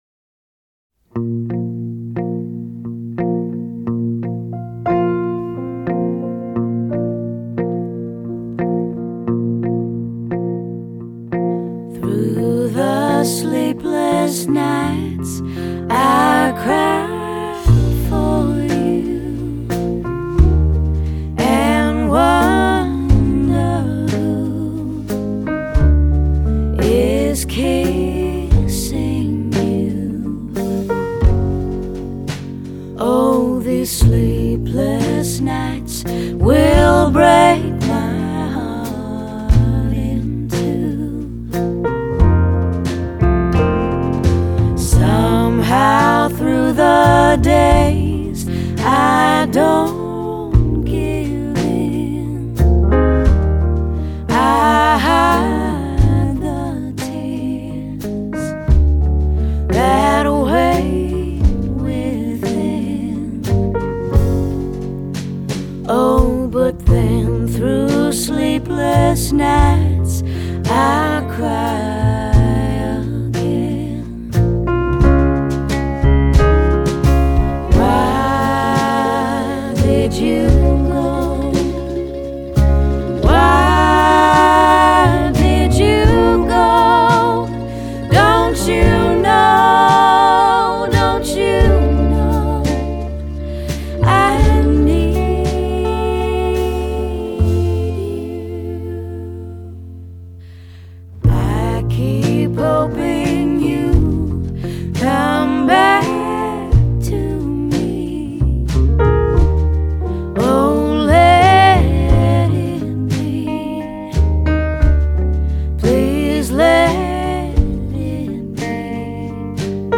类型:爵士